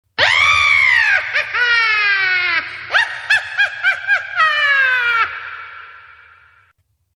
Risada Bruxa Feliz
Risada de uma bruxa feliz.
risada-bruxa-feliz.mp3